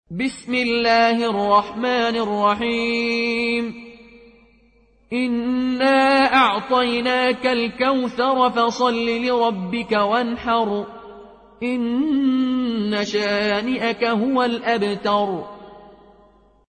قالون عن نافع